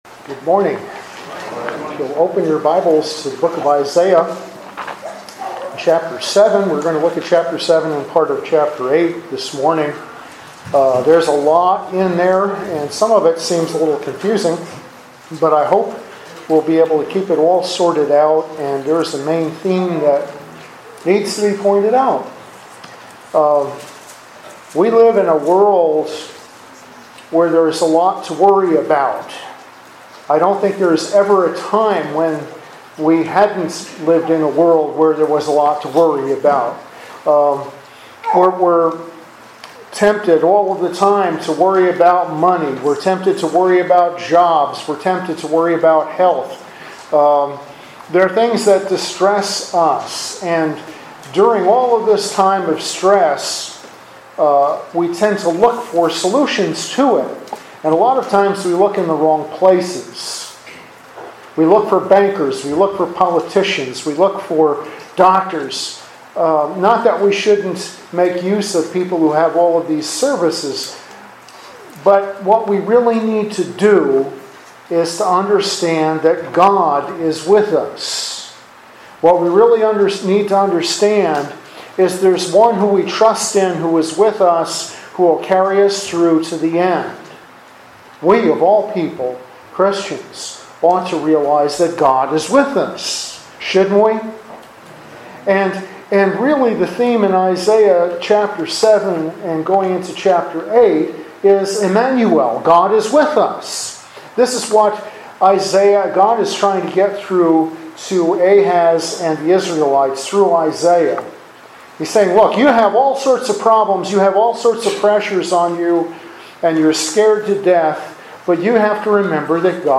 From Sermon Series on Isaiah